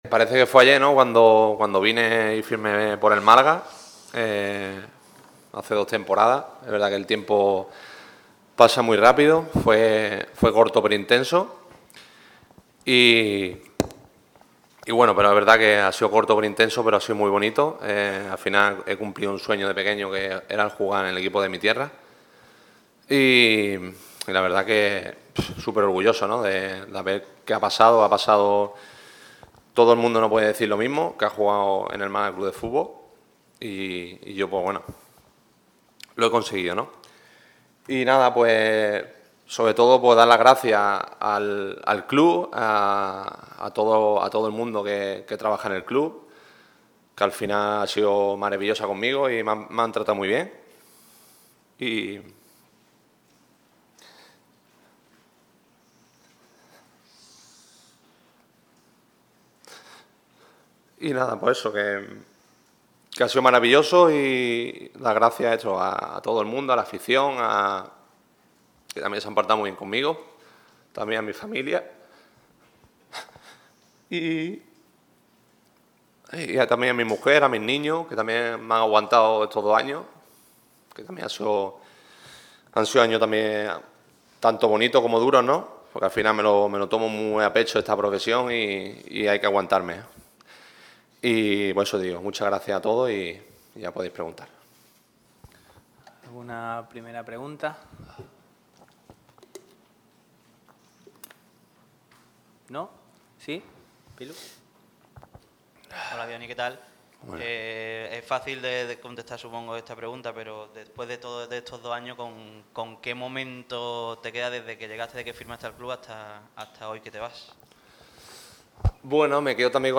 Discurso inicial.